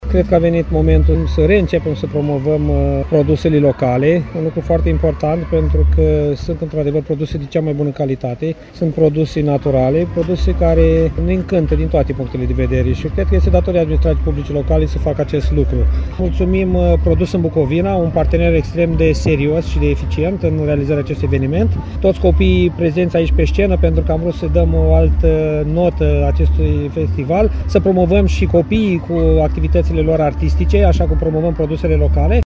Primarul CĂTĂLIN COMAN a salutat reluarea târgului, după pauza impusă de pandemie, precum și noul parteneriat cu Asociația Produs în Bucovina.